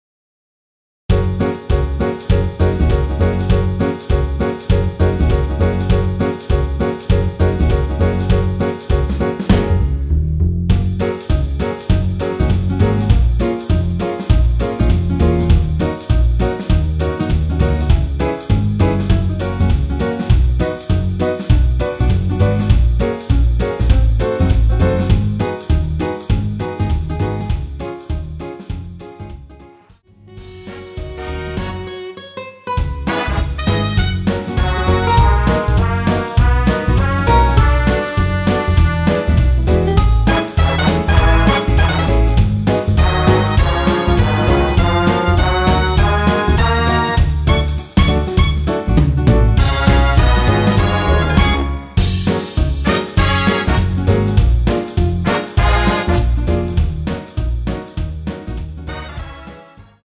대부분의 여성분이 부르실수 있는키로 제작 하였습니다.(미리듣기 참조)
F#
앞부분30초, 뒷부분30초씩 편집해서 올려 드리고 있습니다.
중간에 음이 끈어지고 다시 나오는 이유는